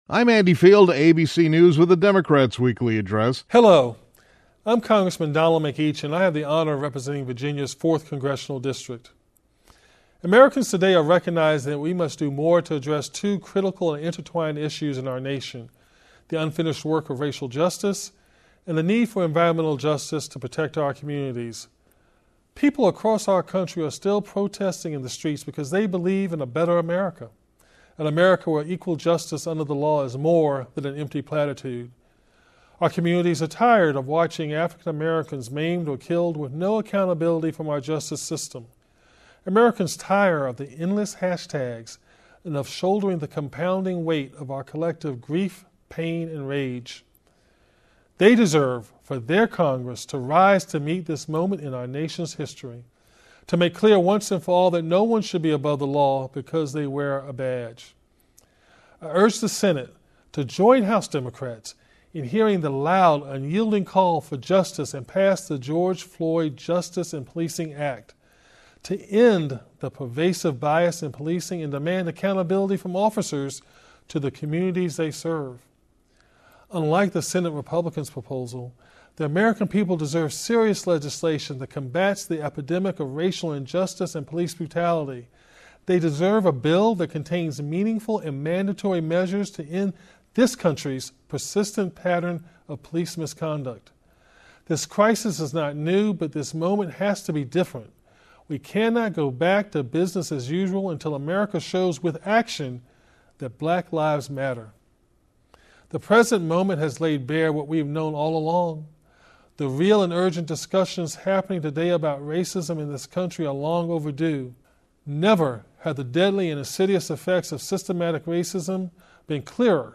During the Democratic Weekly Address, Rep. Donald McEachin (D-VA) stated that more has to be done to deal with racial and environmental injustice.